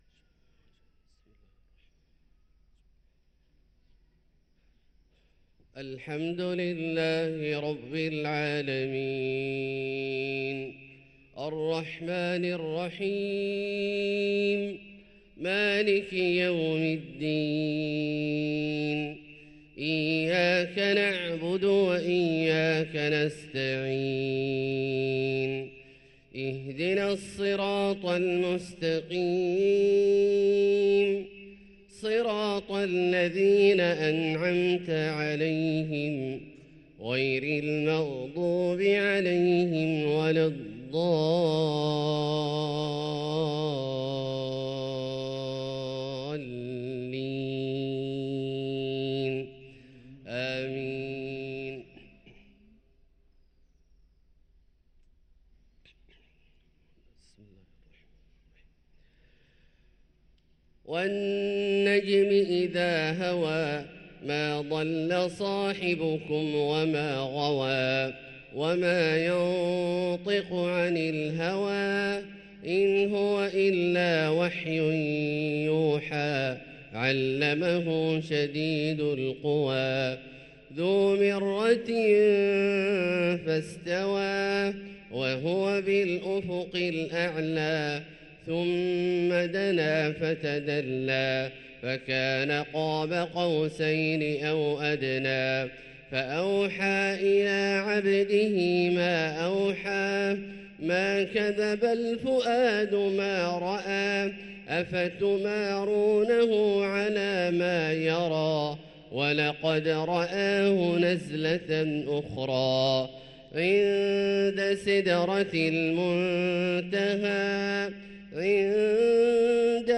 صلاة الفجر للقارئ عبدالله الجهني 29 ربيع الأول 1445 هـ
تِلَاوَات الْحَرَمَيْن .